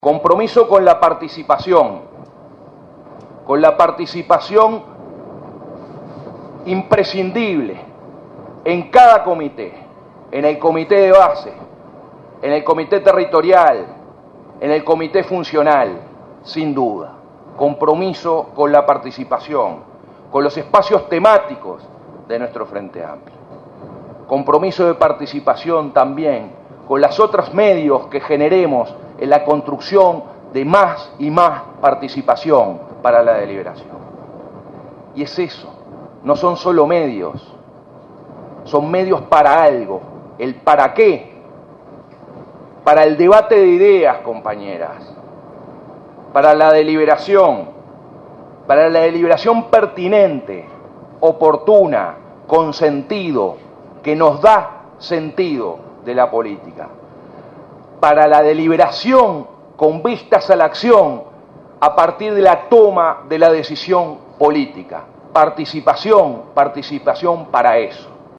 En su discurso aseguró que este fin de semana, con el recambio de autoridades se completa un ciclo de construcción de democracia partidaria.